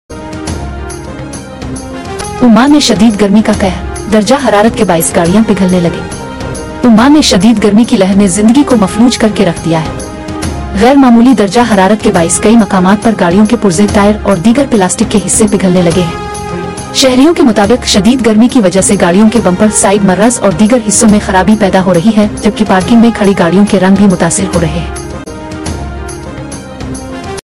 Geo News Headlines Today Sound Effects Free Download